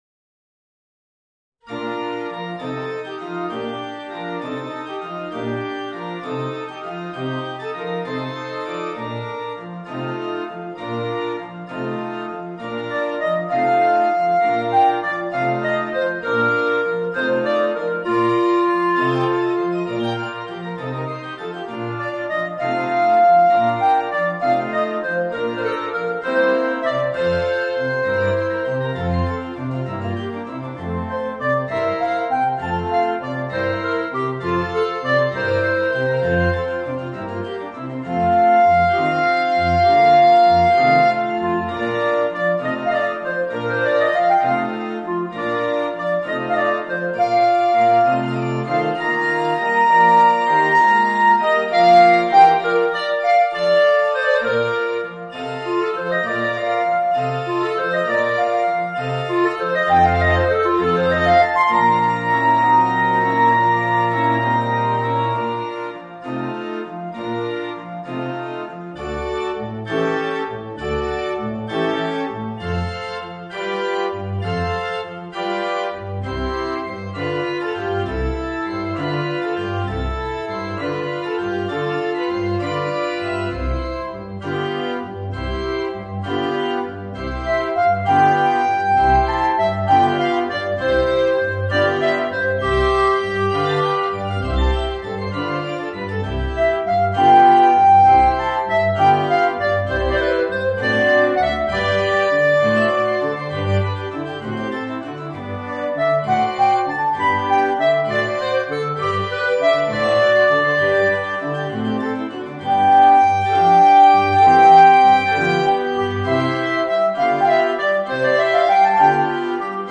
Voicing: Clarinet and Organ